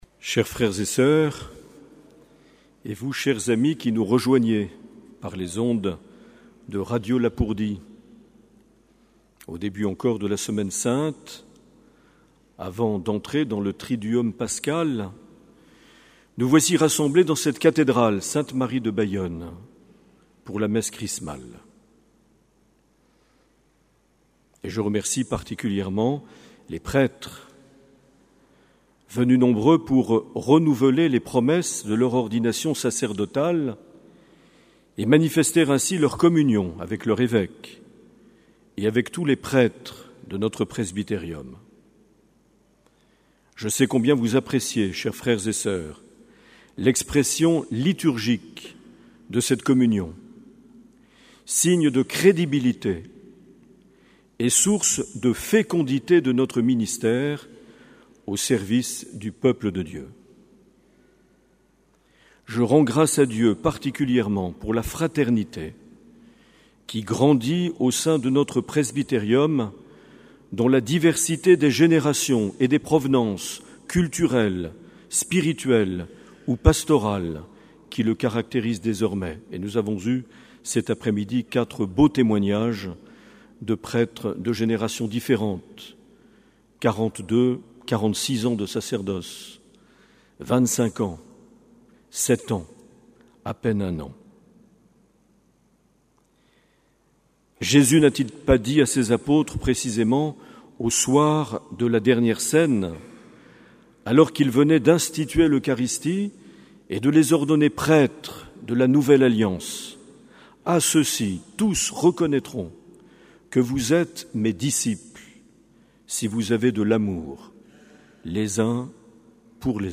27 mars 2018 - Cathédrale de Bayonne - Messe Chrismale
Les Homélies
Une émission présentée par Monseigneur Marc Aillet